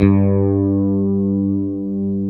Index of /90_sSampleCDs/Roland LCDP02 Guitar and Bass/BS _E.Bass 5/BS _Dark Basses